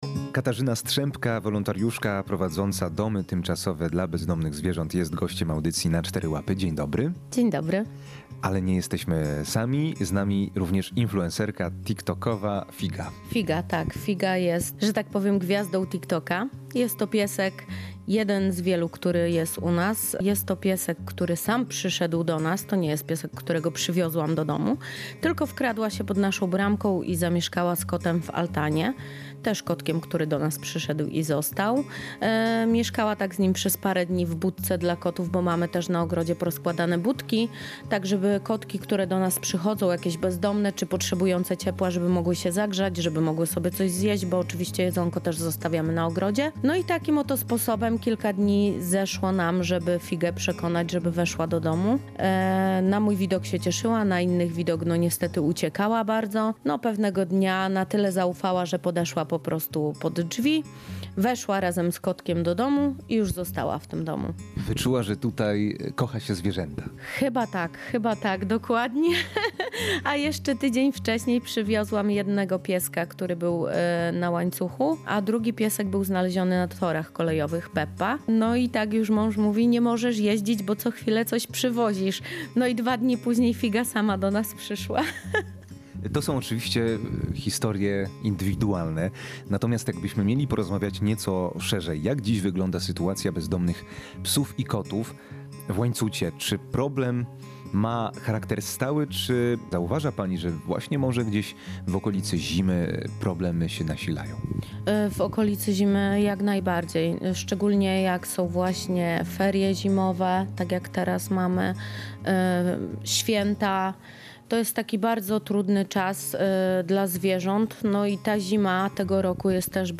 Jak zaznacza nasz gość, w Łańcucie problem bezdomności częściej dotyczy kotów niż psów.